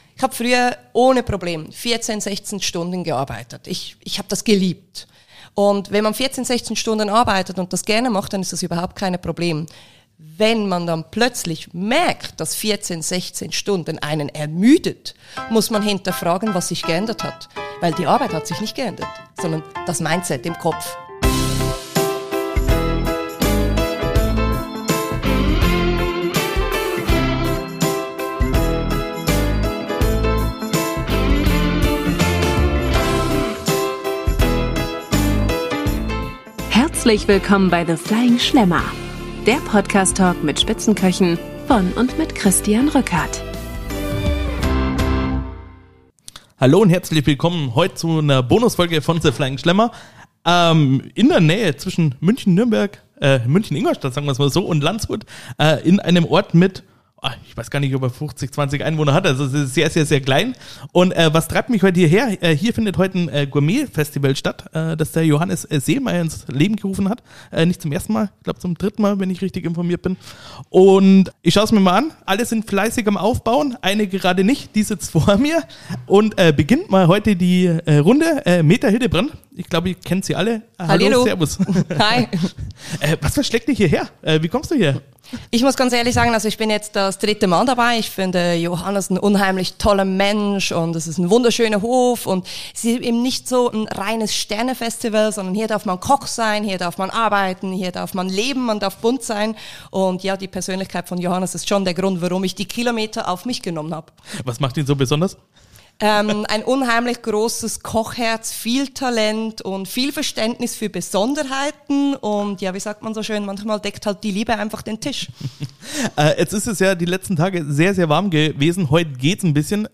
Eine Folge, die vor bayerischer und Schweizer Lebensfreude nur so sprüht, voller kulinarischer Leidenschaft und Geschichten, die Lust auf Sommer, Genuss und Gemeinschaft machen.